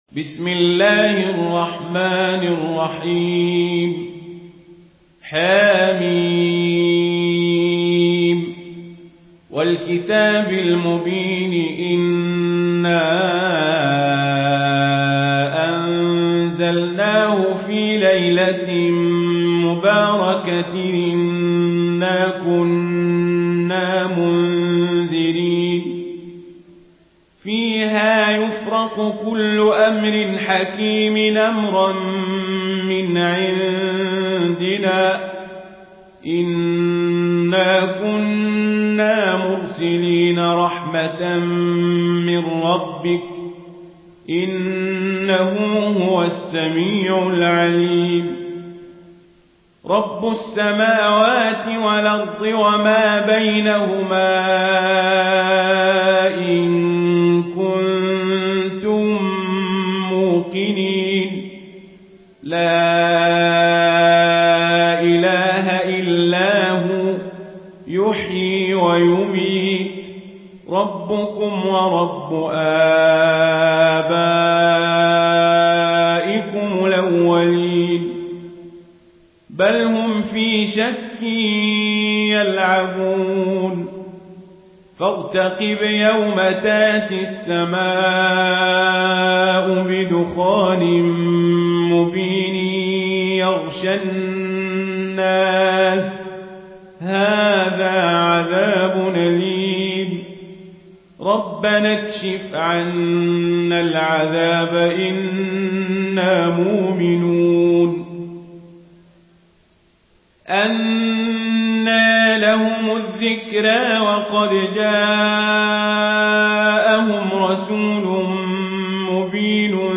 Une récitation touchante et belle des versets coraniques par la narration Warsh An Nafi.
Murattal